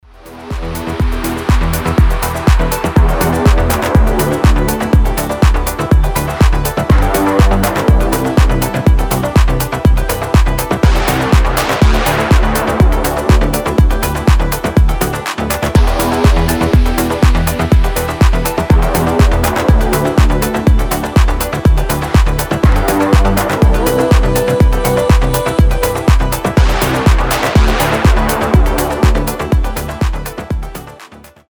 deep progressive
progressive house
electronic